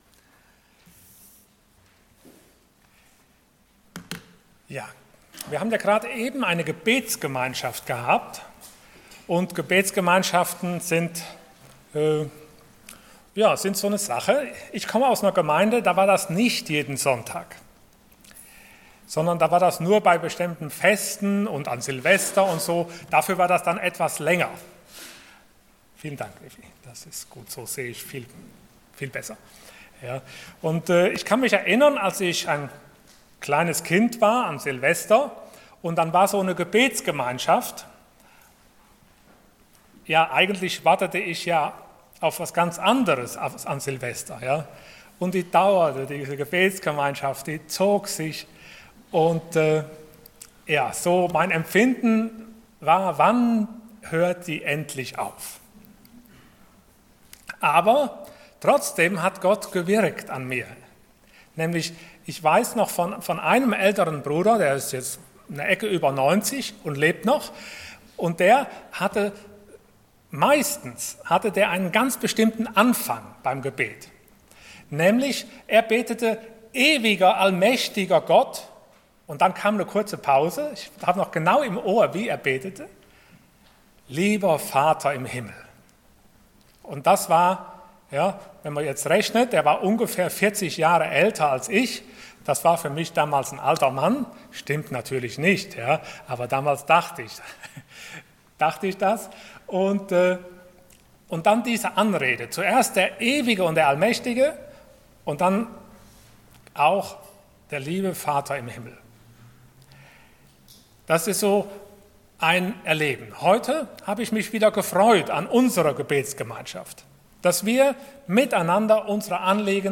Prediger
Passage: Acts 4:21-37 Dienstart: Sonntag Morgen